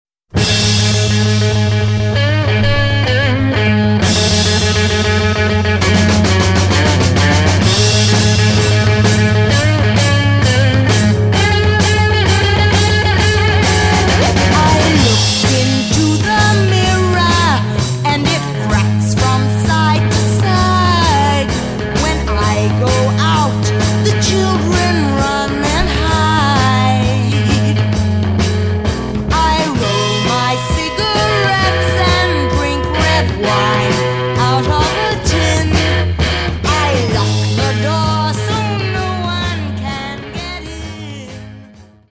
GIRLS POP/FREAK BEAT